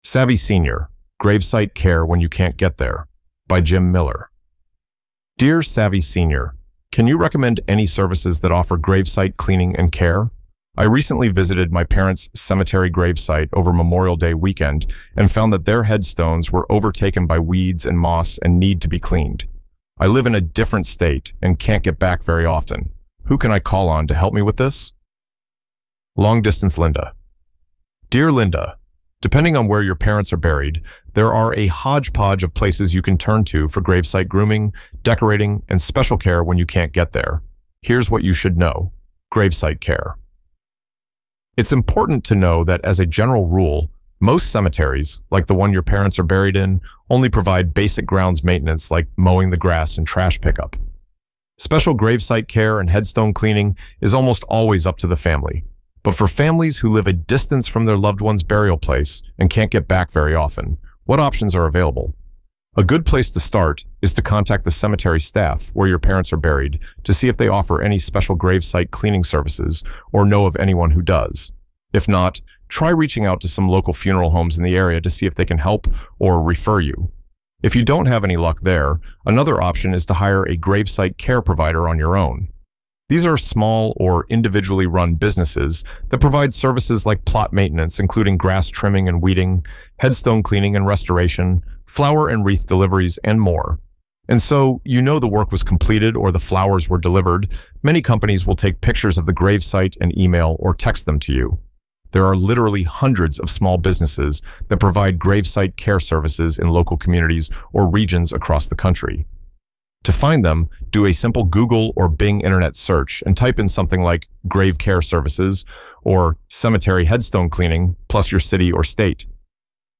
Narrration provided by Wondervox